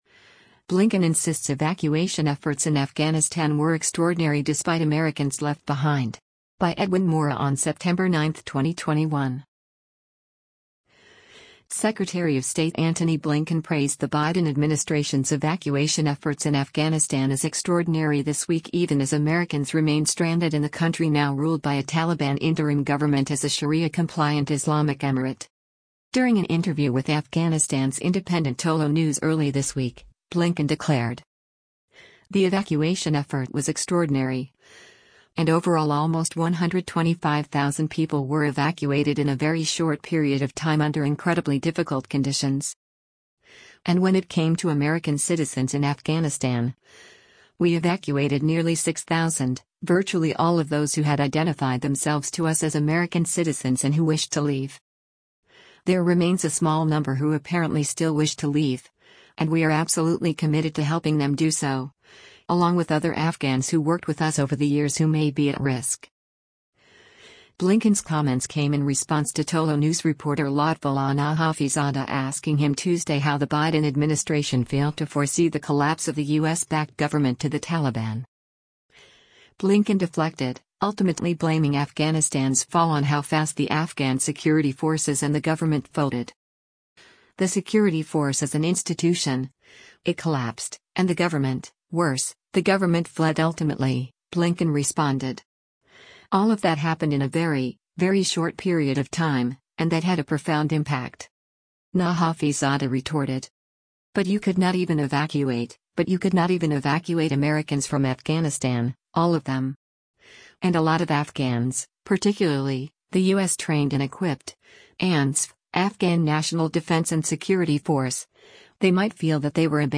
US Secretary of State Antony Blinken delivers remarks following talks on the situation in